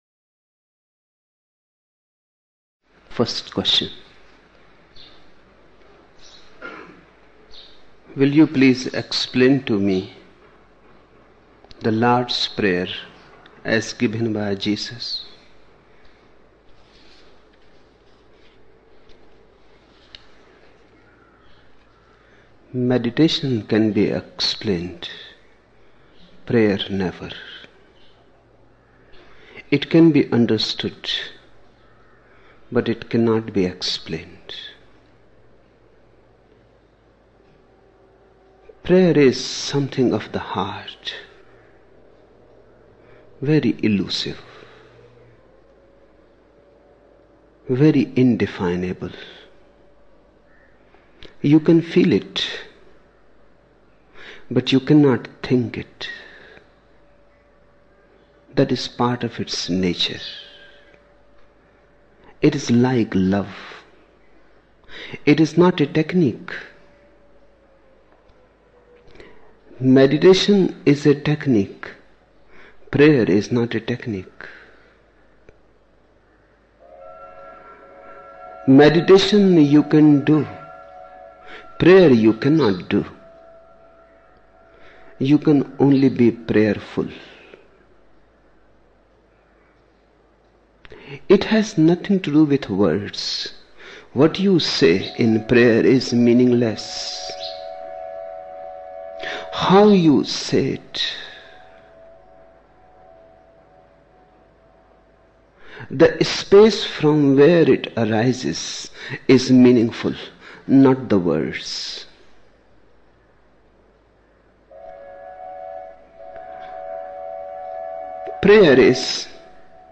20 December 1975 morning in Buddha Hall, Poona, India